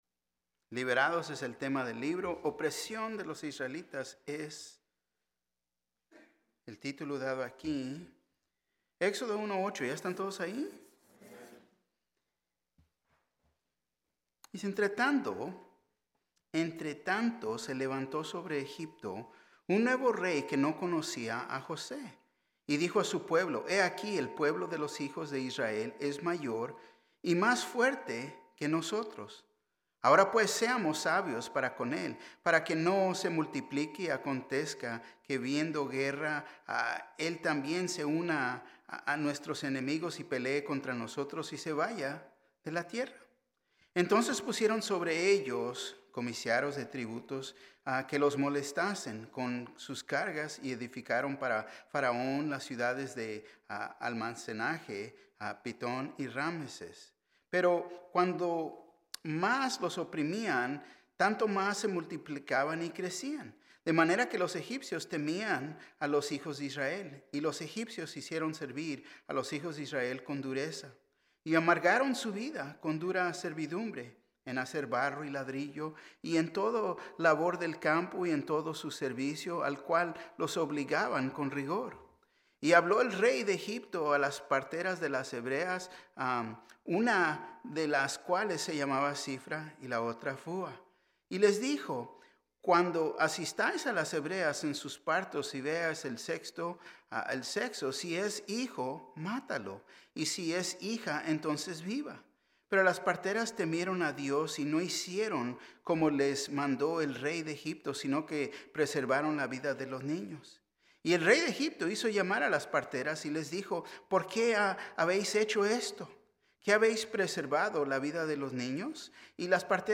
Un mensaje de la serie "Estudios Tématicos." ¿Te Has Postrado Ante El Rey Y Has Doblado Tu Rodilla Ante Jesús? ¿Reconoces Que Cristo Rey Murió Por Tu Pecado?